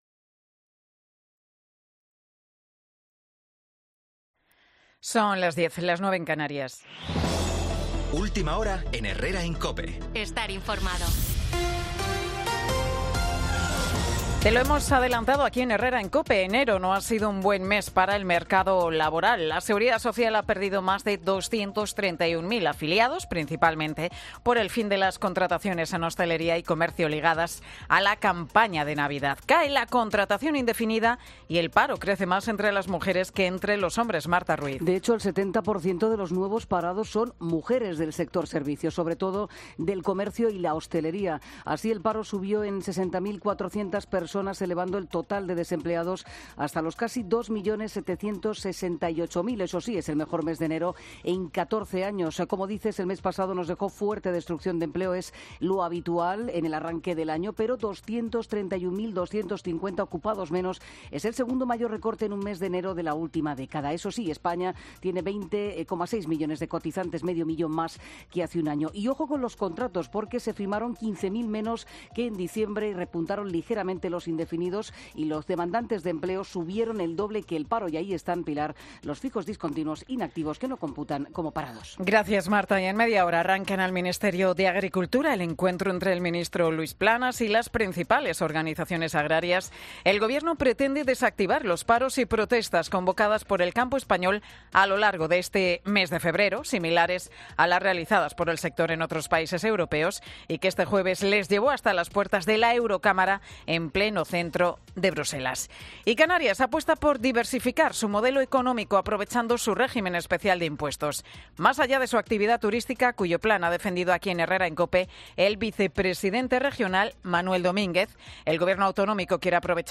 Boletín de Noticias de COPE del 2 de febrero del 2024 a las 10 horas